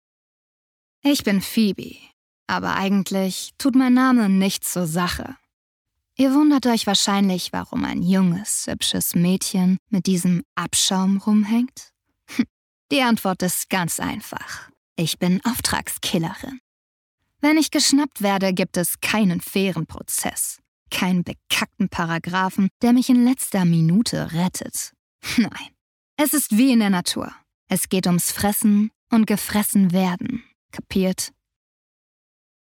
Arrogant, selbstbewusst, zynisch und abgefucked